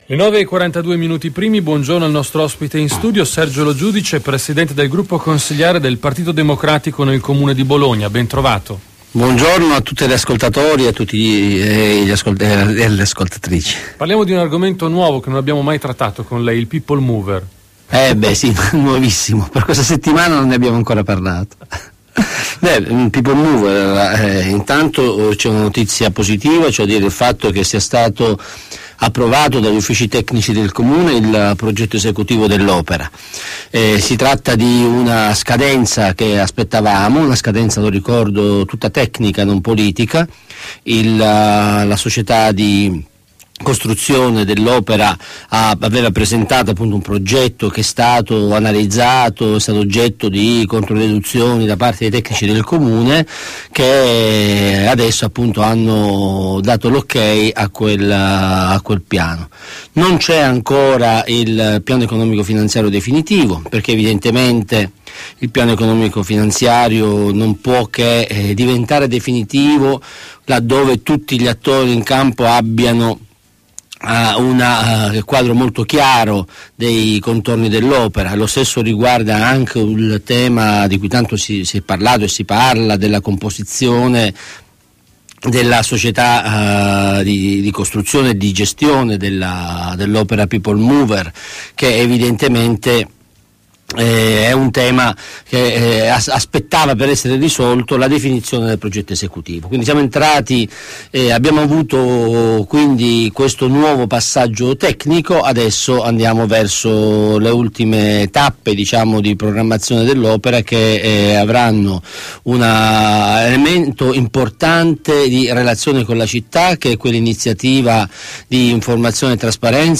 Intervista a Radio Tau del capogruppo PD Sergio Lo Giudice il 16 febbraio 2012